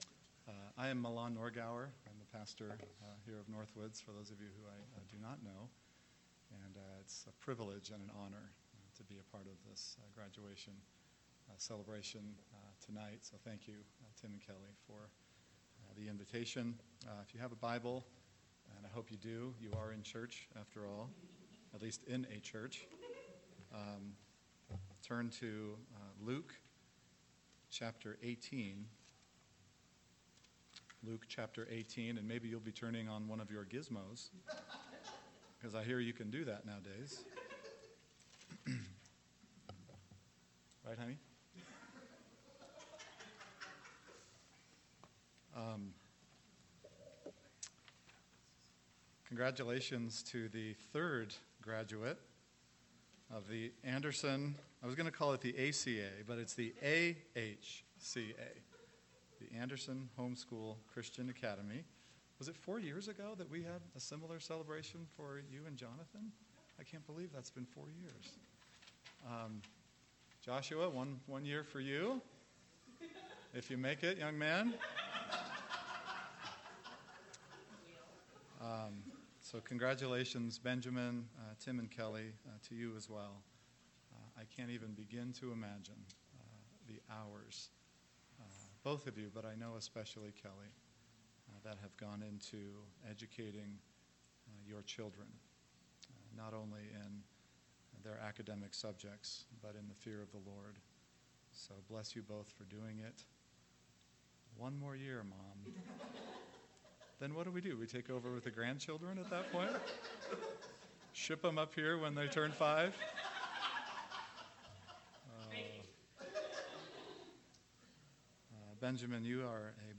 Graduation
BWAgradMessage.mp3